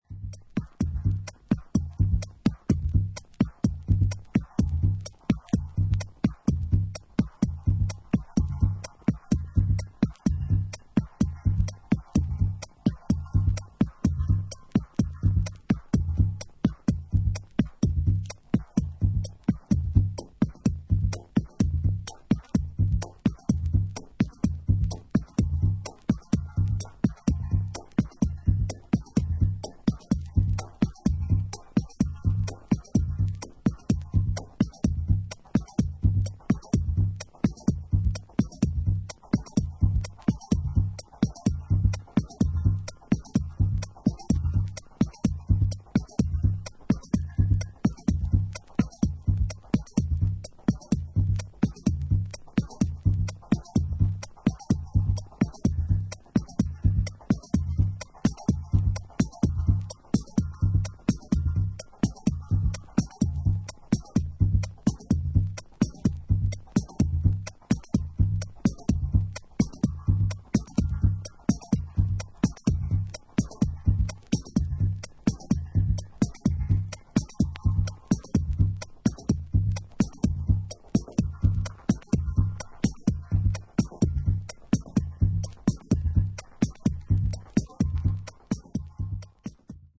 迫力のあるベースを軸にしたシーケンスで、永遠に続くかのように錯覚するグルーヴを保ったダブテクノ二曲を収録した本作。